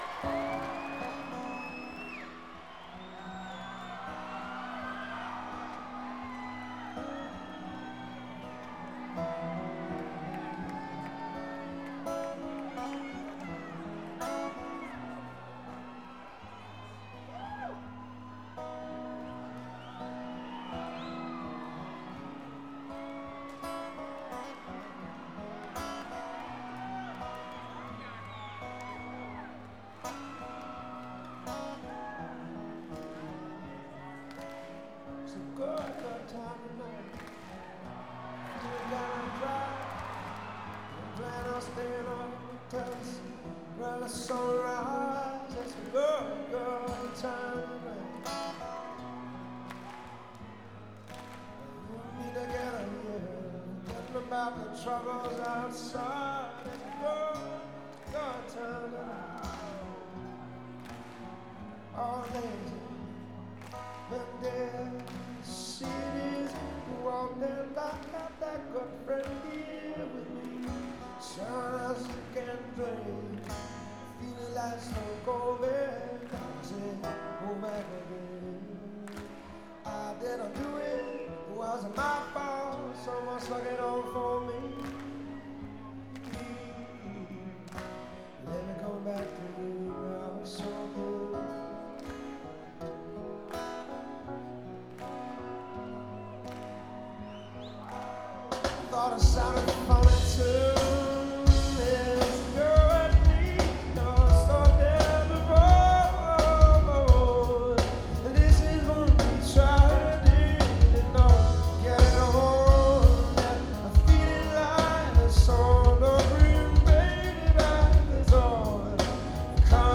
7.26.2004 Alltel Pavillion at Walnut Creek, Raleigh, NC 8:03